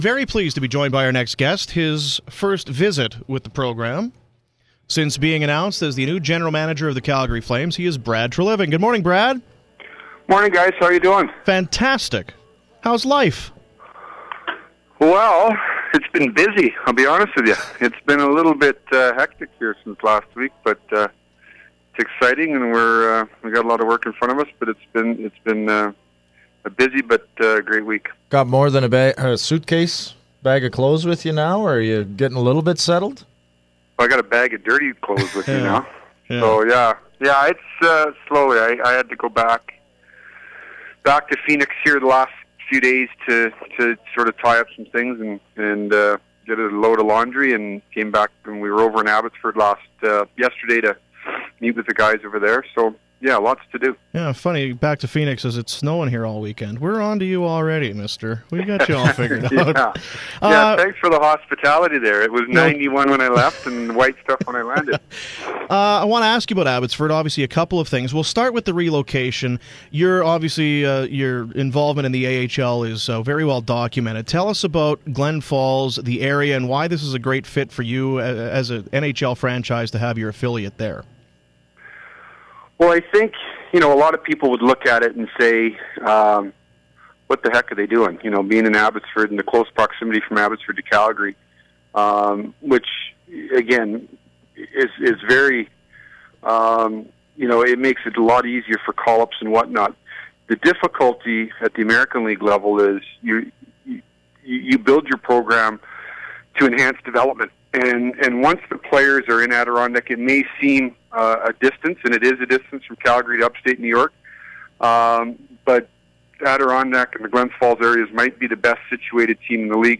Full Treliving Fan960 interview.